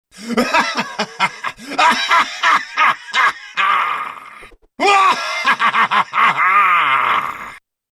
Risada Jhin (LoL)
Risada do psicopata Jhin de League Of Legends (LoL).
risada-jhin-lol.mp3